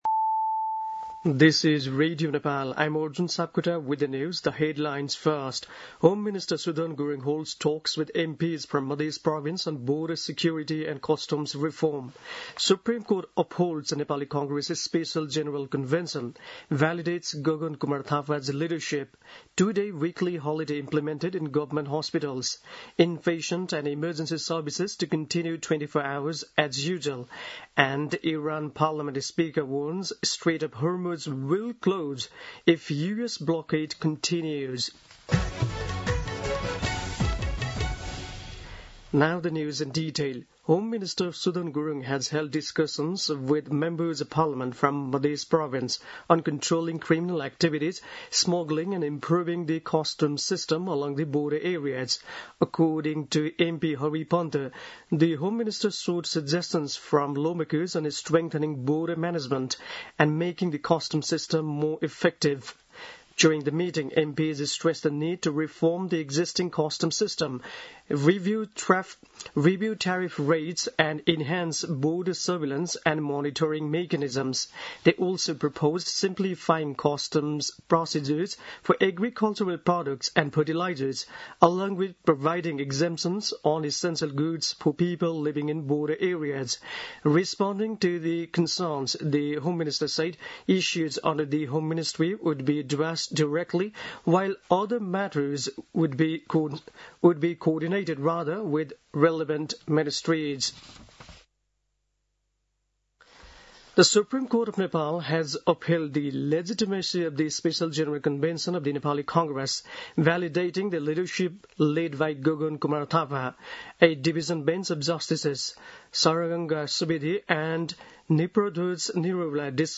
दिउँसो २ बजेको अङ्ग्रेजी समाचार : ५ वैशाख , २०८३